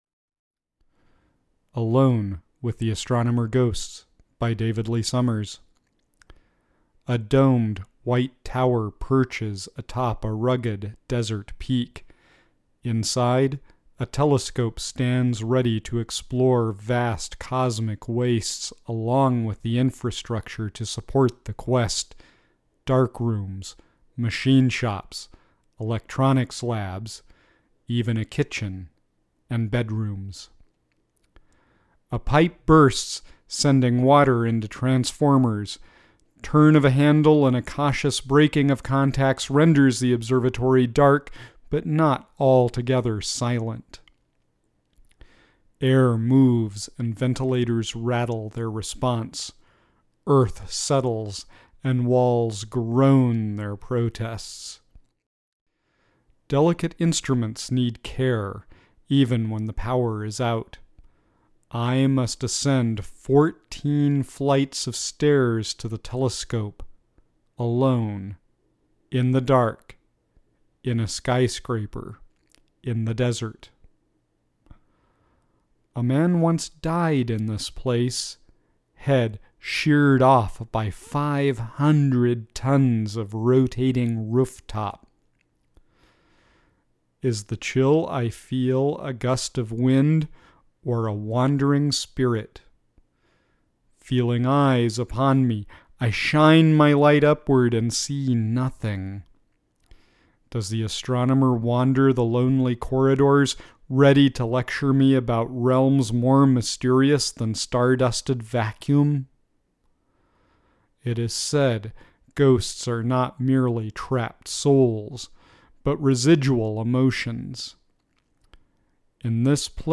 2012 Halloween Poetry Reading